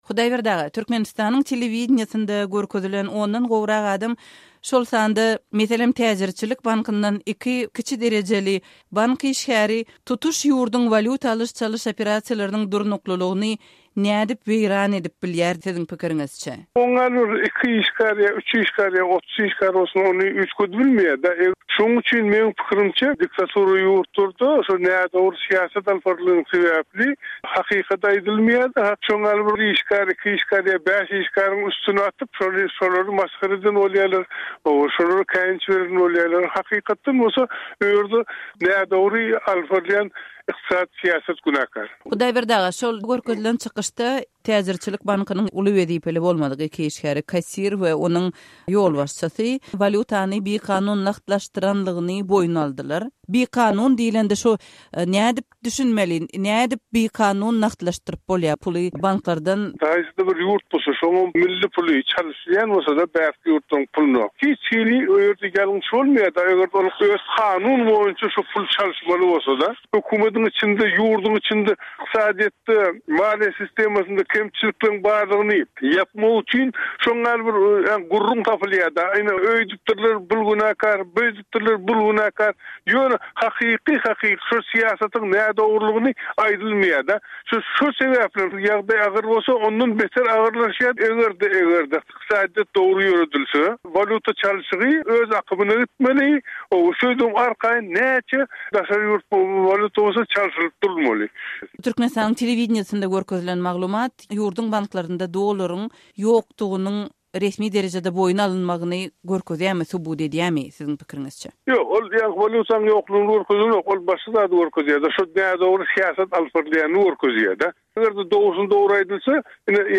Azatlyk Radiosy Türkmenistanyň Merkezi Bankynyň öňki ýolbaşçysy, ykdysadyýetçi Hudaýberdi Orazowdan pikir sorady.